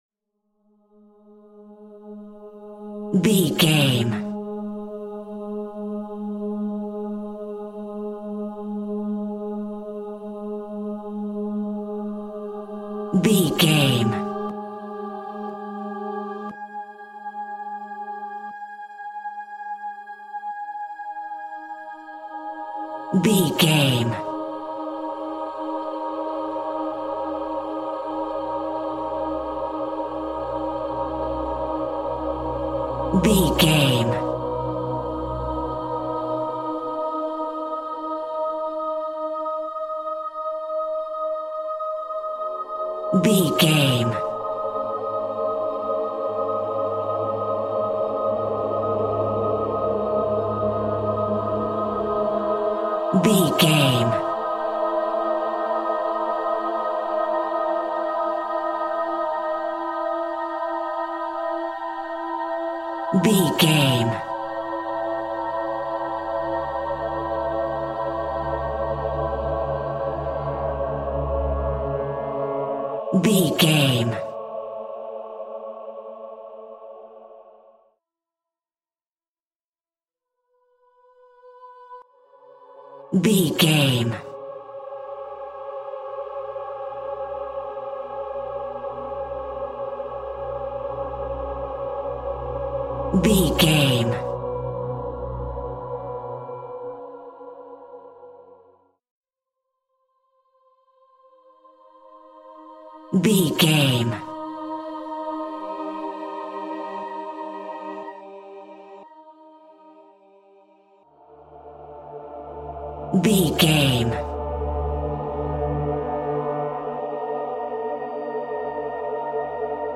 Aeolian/Minor
A♭
ominous
dark
haunting
eerie
synthesizer
ambience
pads
eletronic